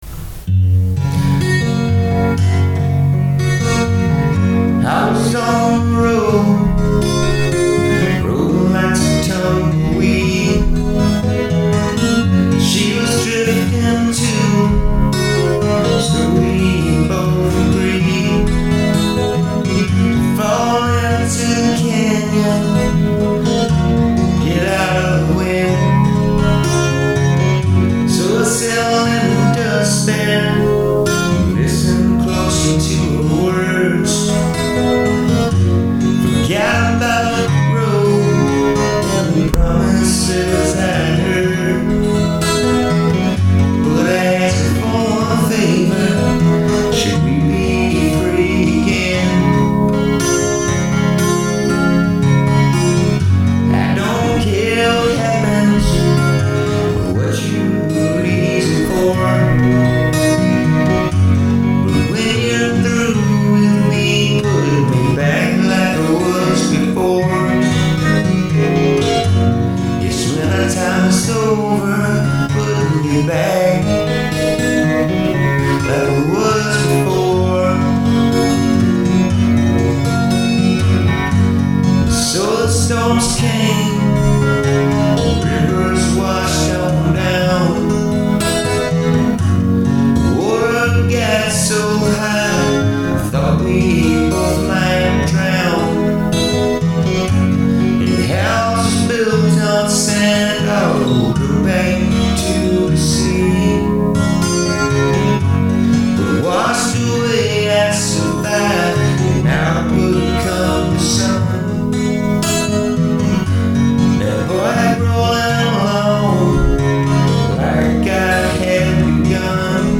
Country
Folk
Country-rock